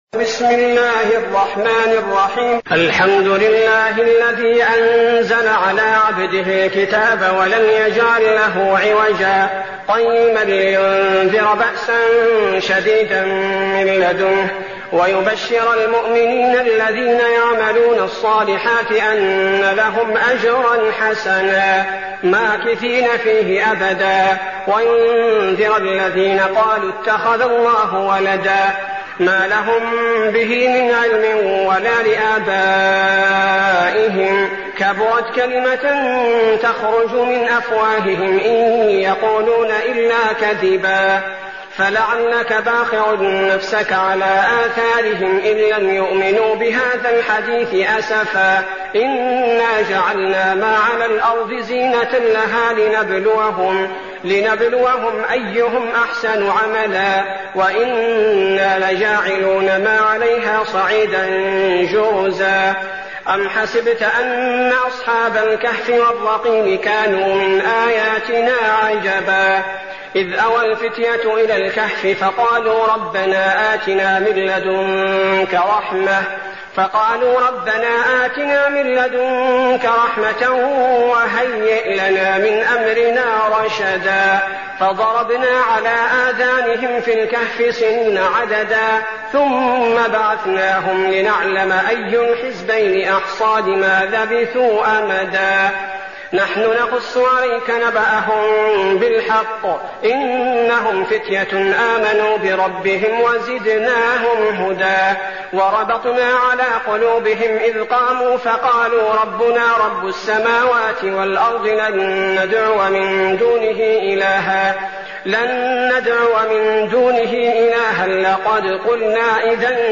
المكان: المسجد النبوي الشيخ: فضيلة الشيخ عبدالباري الثبيتي فضيلة الشيخ عبدالباري الثبيتي الكهف